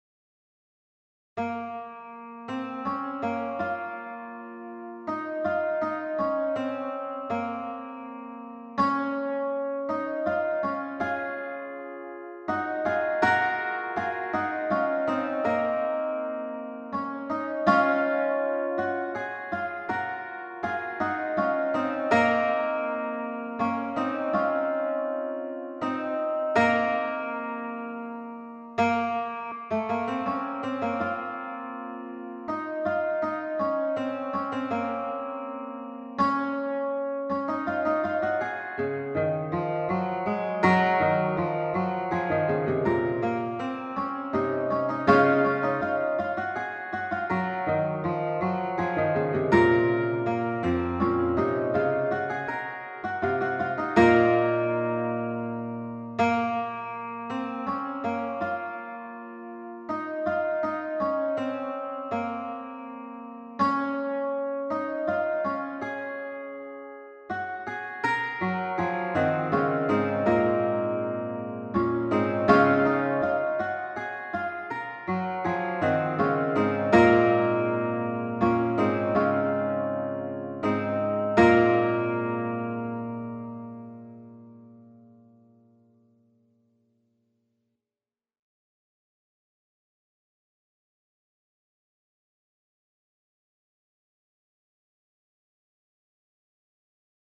in c -